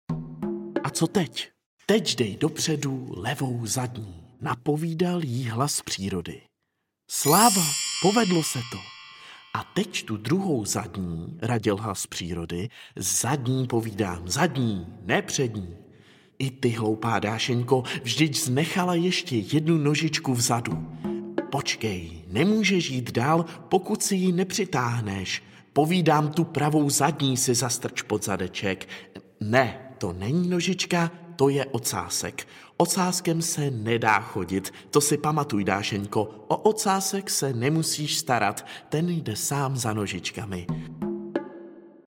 Dášeňka čili život štěněte audiokniha
Audio kniha
Ukázka z knihy